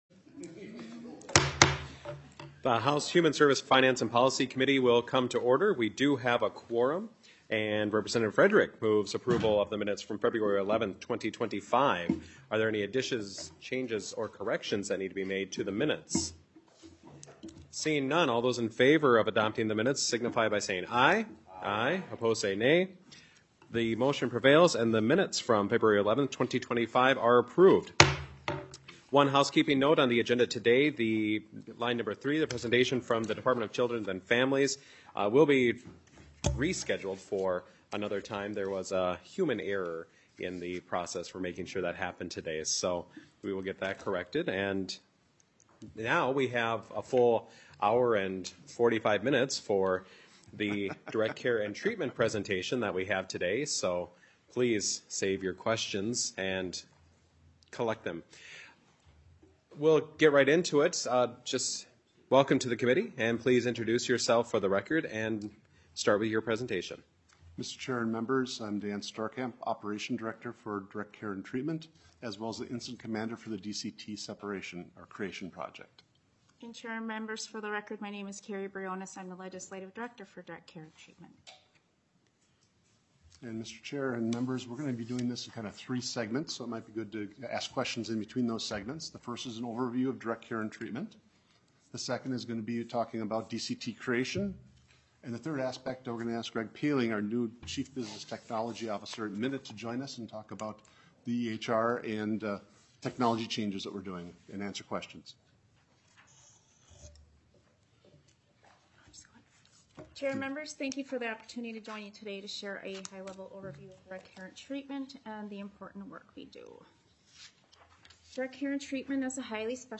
*This is an informational hearing, public testimony will not be taken